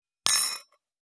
249,食器にスプーンを置く,ガラスがこすれあう擦れ合う音,グラス,コップ,工具,小物,雑貨,コトン,トン,ゴト,ポン,ガシャン,ドスン,ストン,カチ,タン,
コップ効果音厨房/台所/レストラン/kitchen物を置く食器